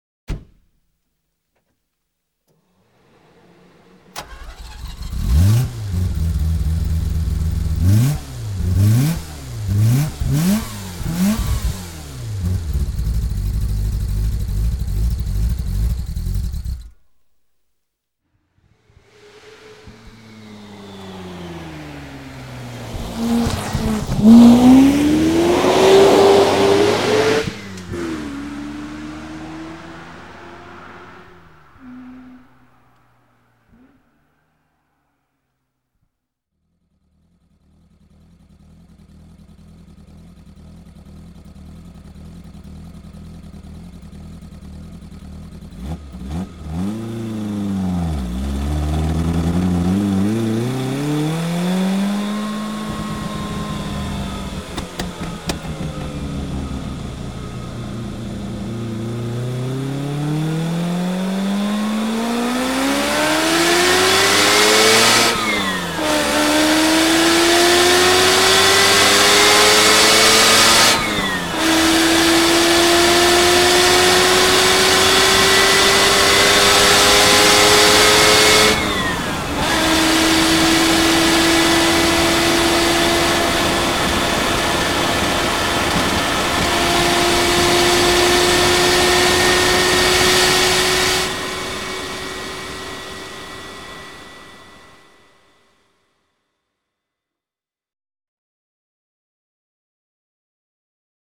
PS: Als „Vorgeschmack“ hier einmal der Wankel-Sound von der Motoren-Sound-Schallplatte die zum 100-jährigen Geburtstag des Automobils herausgegeben wurde
Mercedes_C111-sound.mp3